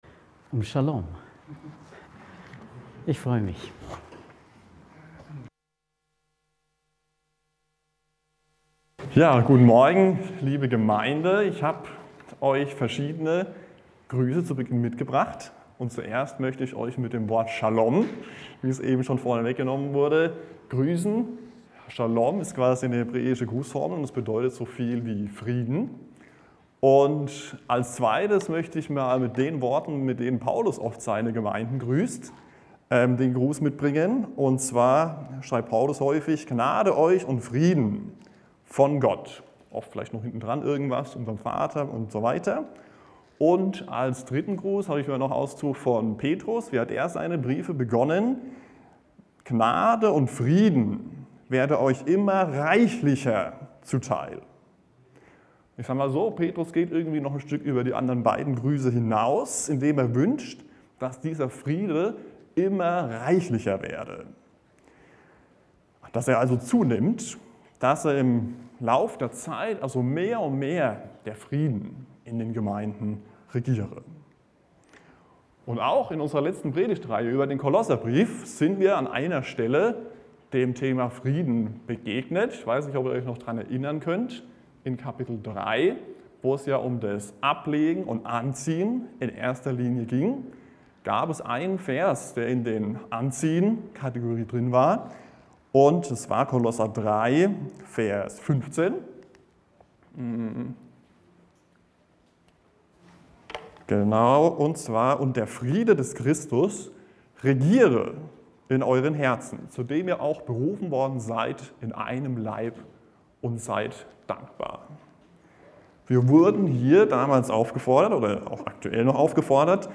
In der heutigen Predigt ging es um folgende Punkte: Gottes Friede ist der einzig wahre Friede! Lass den Frieden in deinem Herzen regieren und jage ihm nach!